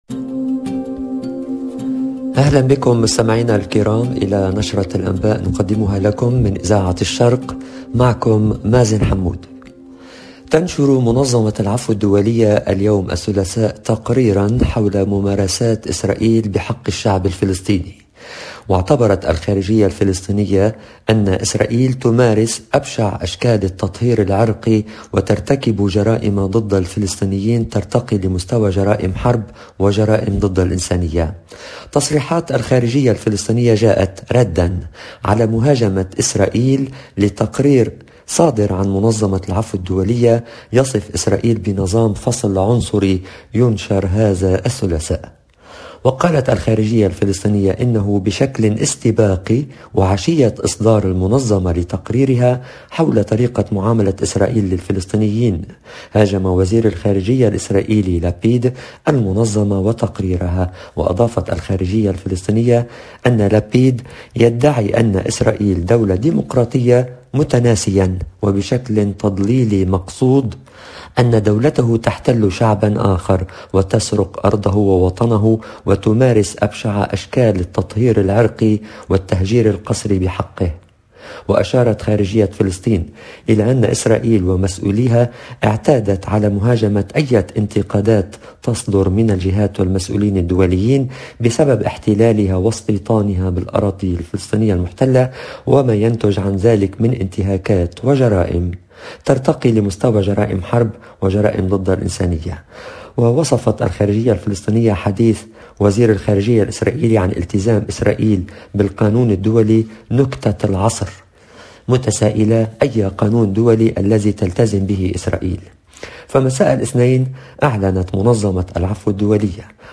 LE JOURNAL DE MIDI 30 EN LANGUE ARABE DU 1/02/22